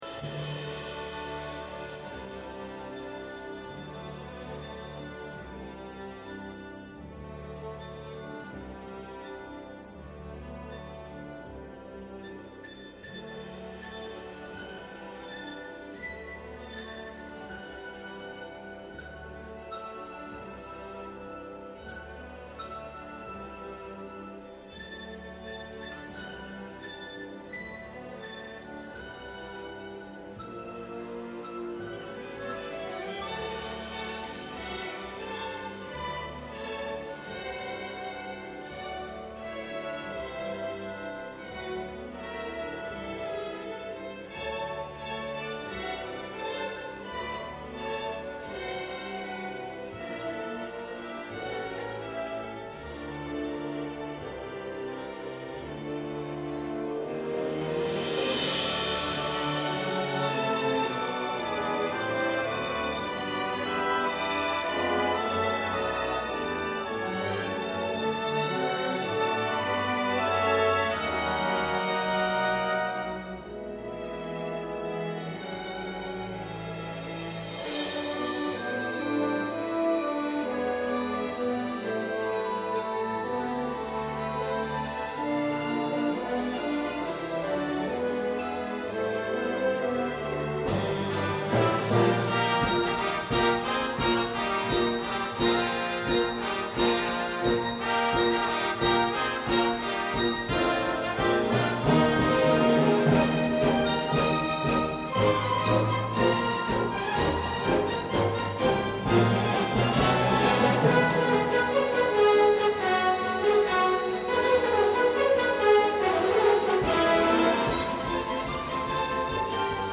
Please note: These samples are of varying quality.
Most were taken from live performances and are intended